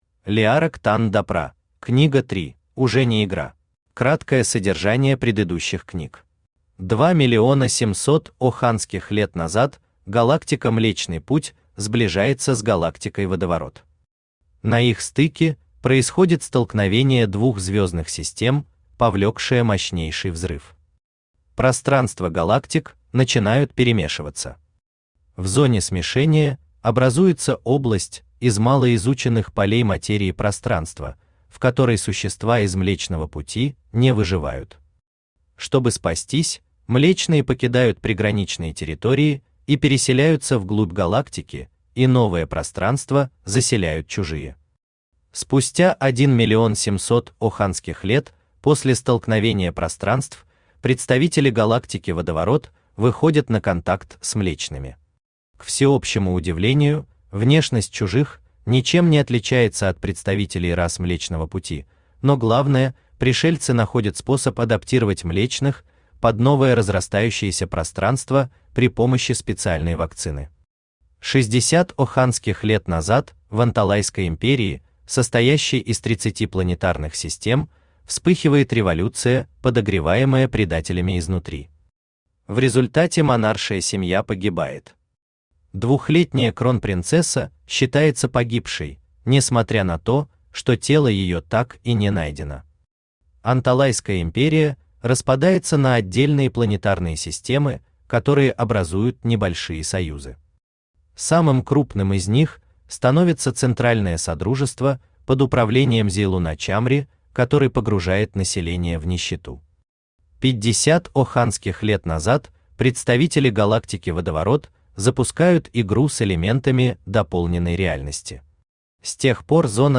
Аудиокнига Допра. Книга 3. Уже не игра | Библиотека аудиокниг
Aудиокнига Допра. Книга 3. Уже не игра Автор Леарок Танн Читает аудиокнигу Авточтец ЛитРес.